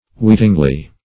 weetingly - definition of weetingly - synonyms, pronunciation, spelling from Free Dictionary Search Result for " weetingly" : The Collaborative International Dictionary of English v.0.48: Weetingly \Weet"ing*ly\, adv.